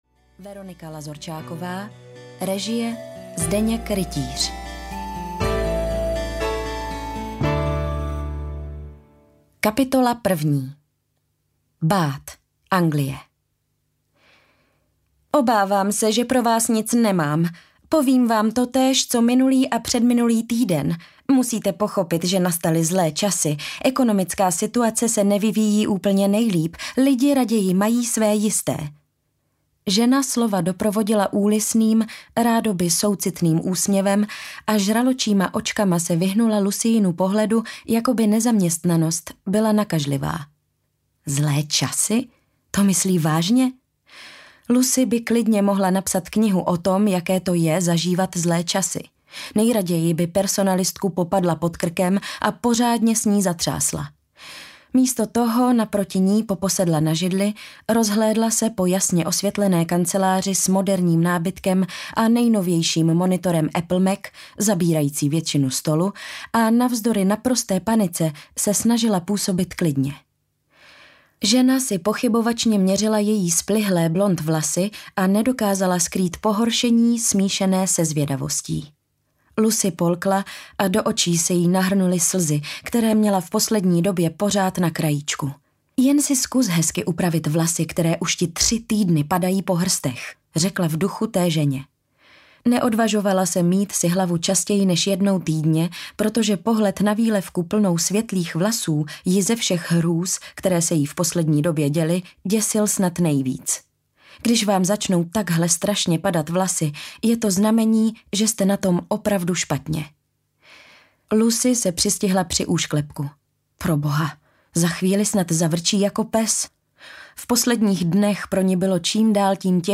Hotýlek na Islandu audiokniha
Ukázka z knihy